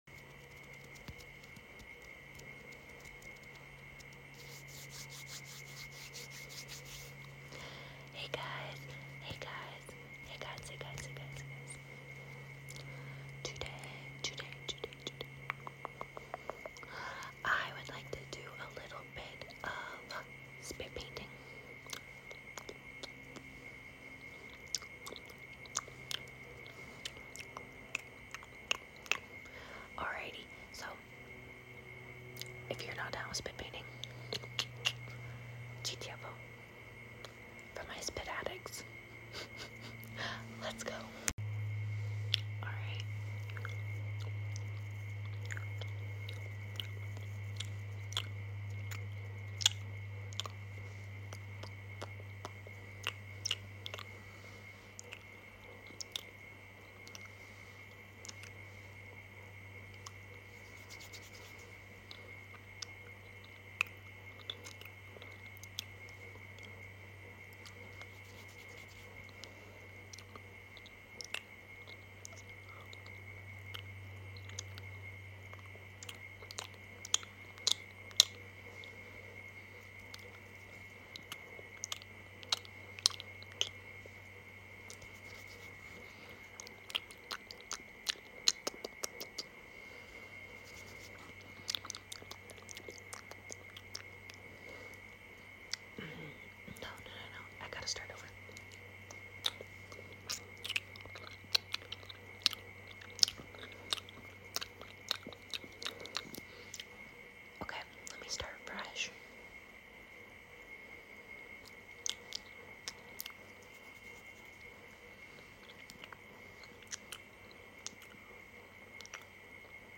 *rings bell* spit painting is sound effects free download
by popular demand, another mouth sounds & spit painting video thank uuuuu again for 2k followers !!!